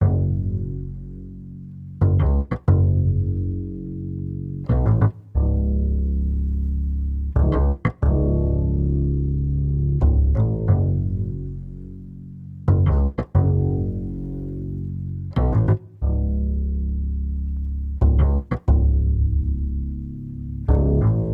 bass2